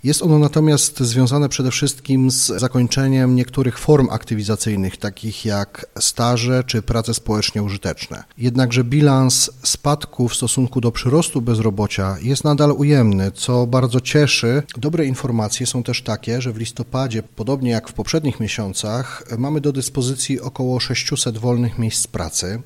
Jest ono związane z niektórymi czynnikami, mówi dyrektor Powiatowego Urzędu Pracy w Radomiu Sebastian Murawski: